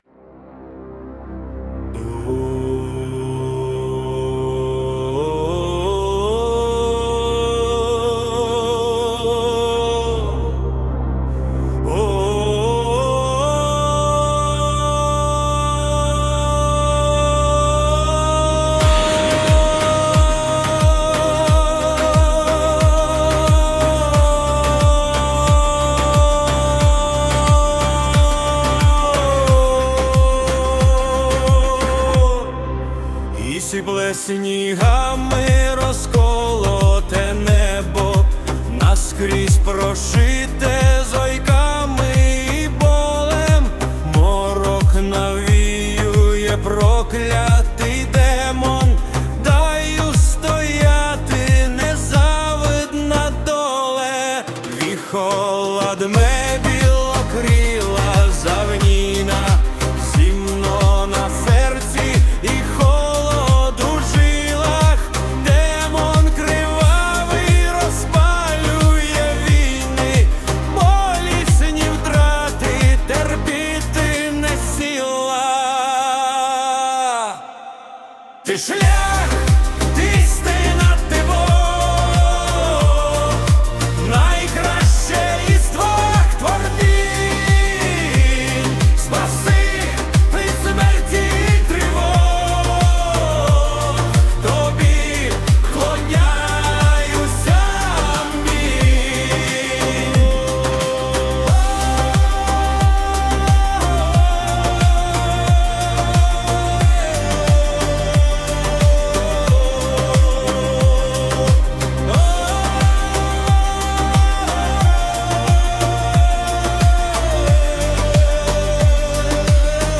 текст авторський ...музика і виконання ШІ